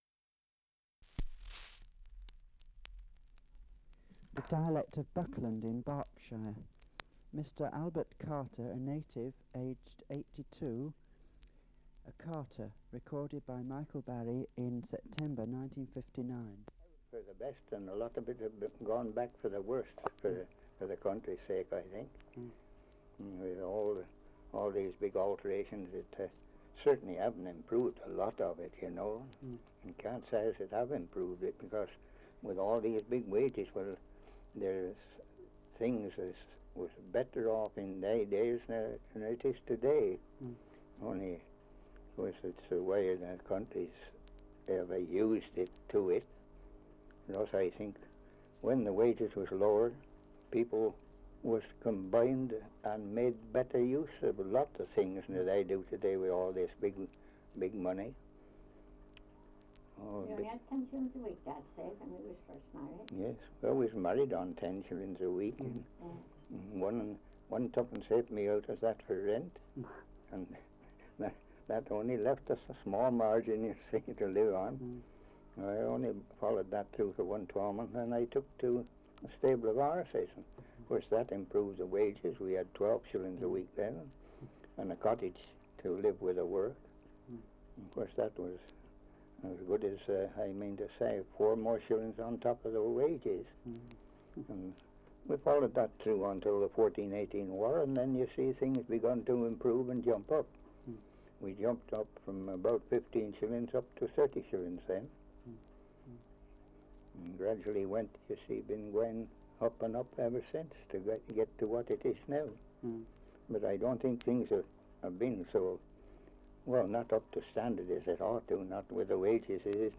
Survey of English Dialects recording in Buckland, Berkshire
78 r.p.m., cellulose nitrate on aluminium